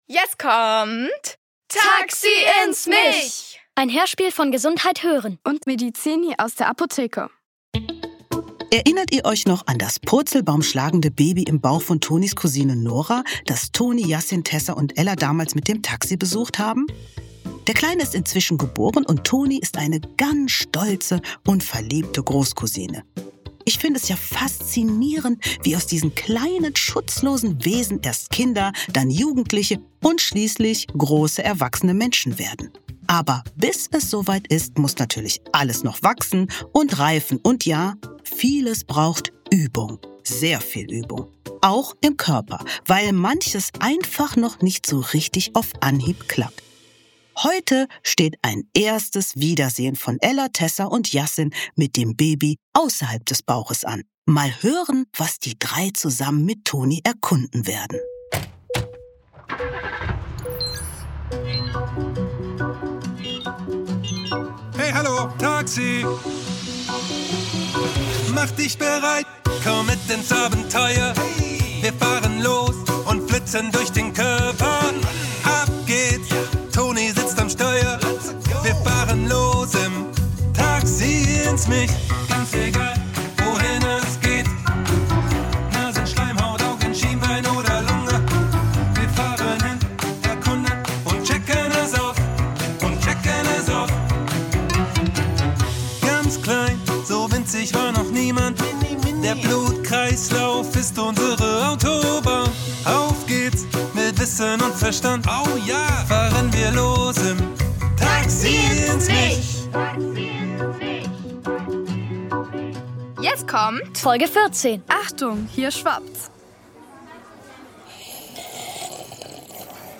Achtung, hier schwappt's! ~ Taxi ins Mich | Der Hörspiel-Podcast für Kinder Podcast